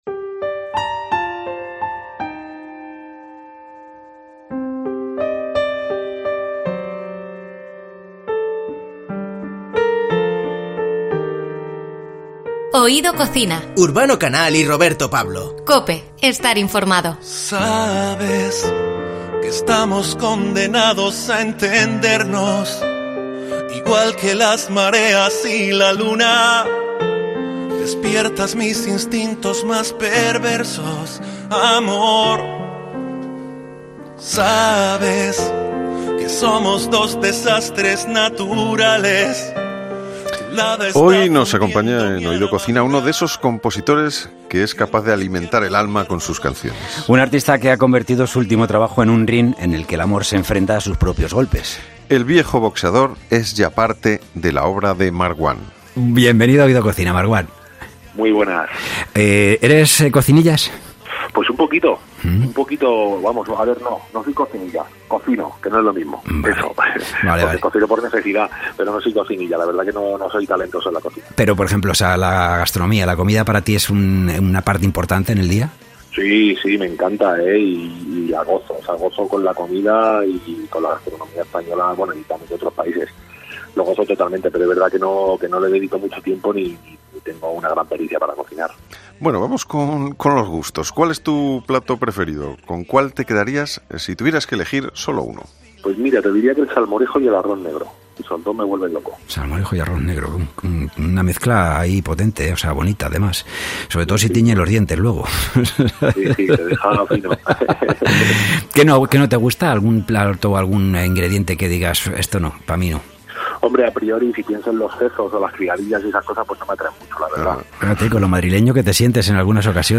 Si te apetece saber cuáles son los gustos del cantante, no te pierdas la charla que hemos mantenido con él en 'Oído Cocina'